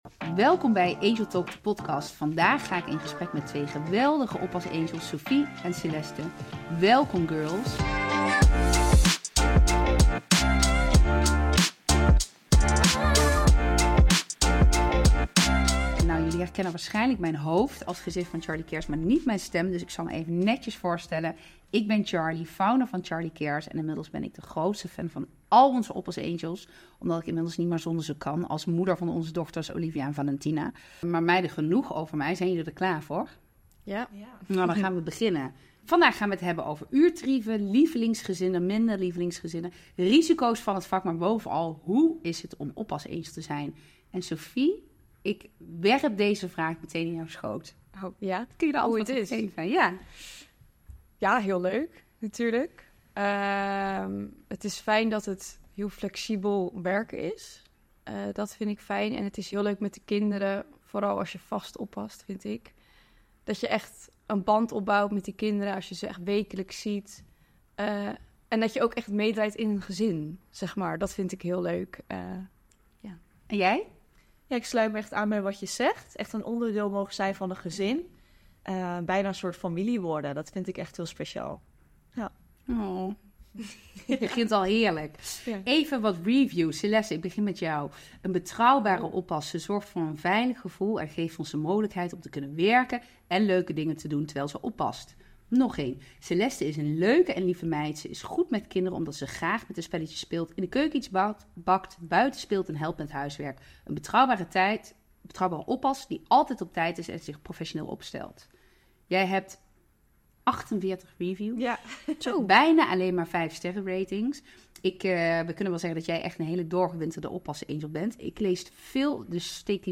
Elke aflevering schuiven 2 Oppas Angels aan en delen ze al hun inzichten.